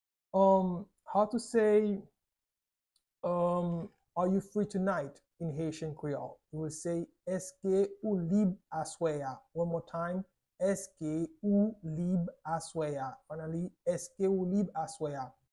Pronunciation:
“Èske ou lib aswè a?” Pronunciation in Haitian Creole by a native Haitian can be heard in the audio here or in the video below: